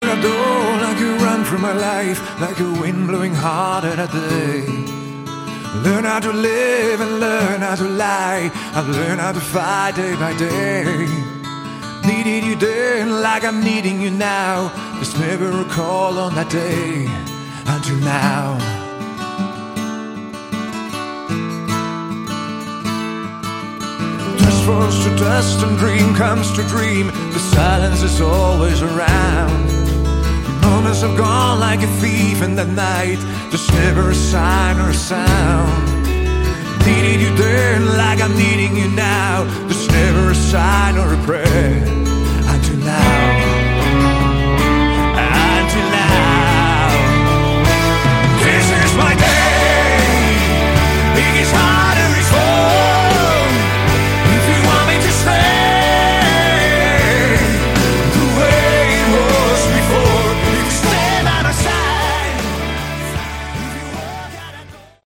Category: Hard Rock
lead guitars, vocals
bass
keyboards, backing vocals
drums